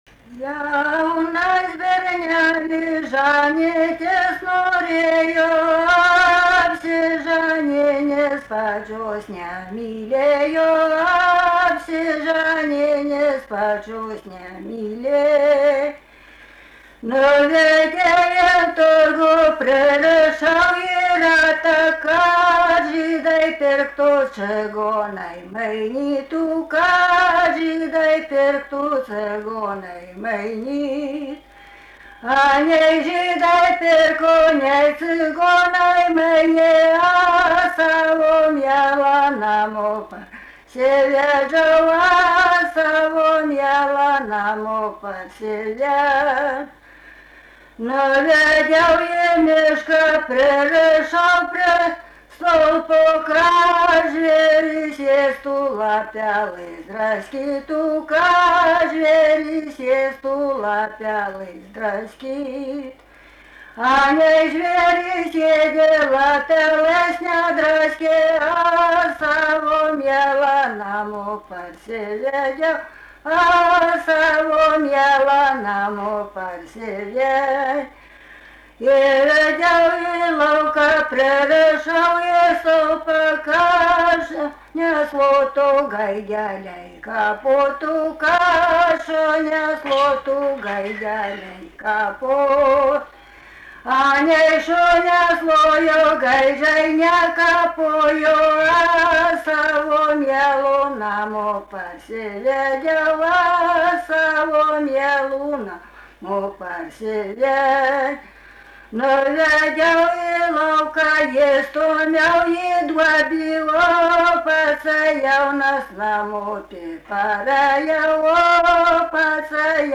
daina
Mardasavas
vokalinis